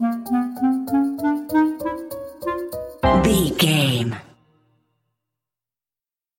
Uplifting
Aeolian/Minor
flute
oboe
strings
orchestra
cello
double bass
percussion
silly
goofy
cheerful
perky
Light hearted
quirky